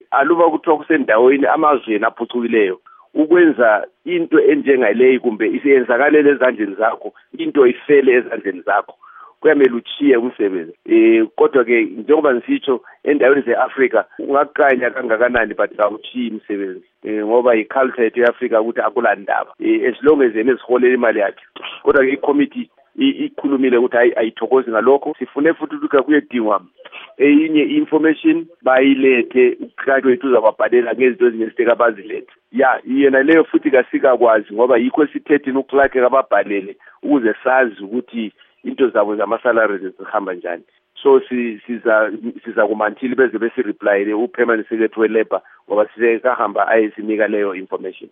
Ingxoxo loMnu. Sam Sipepa Nkomo